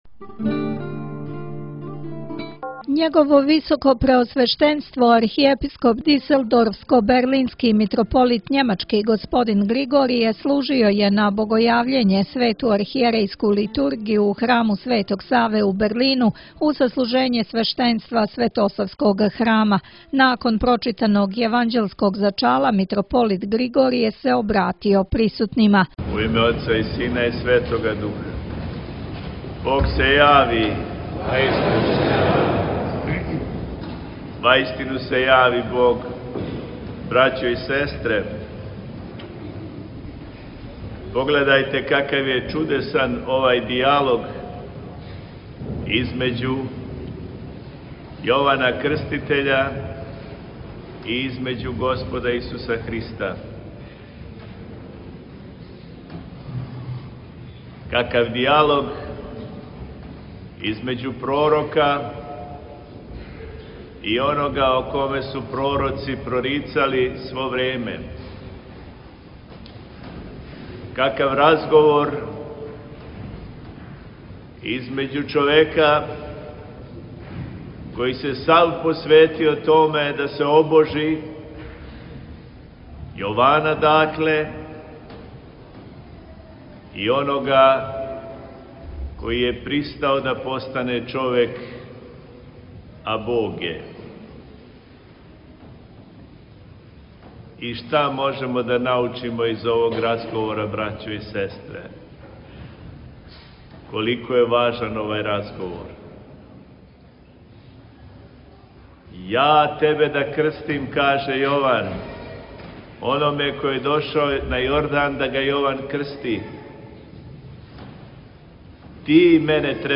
Прослава Богојављења у храму Светога Саве у Берлину